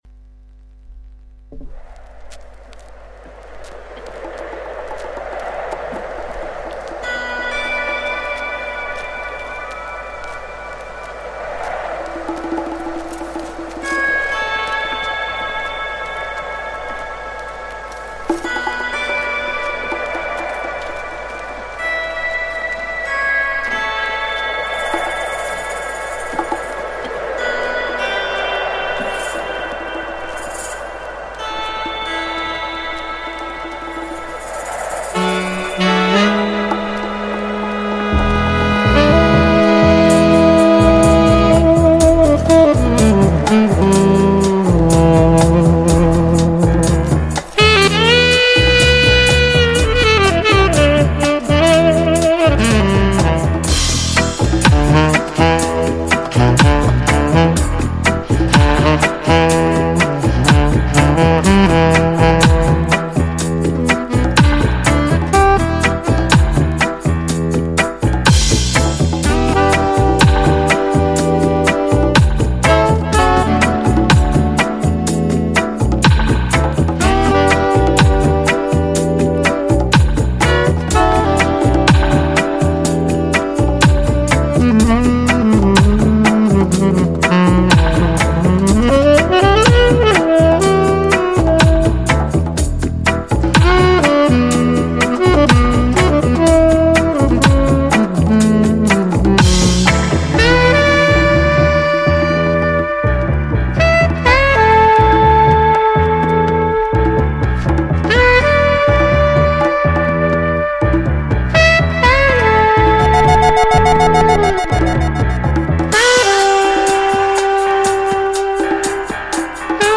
Every week,one hour with reggae music!Only vinyl!Big Up all listeners!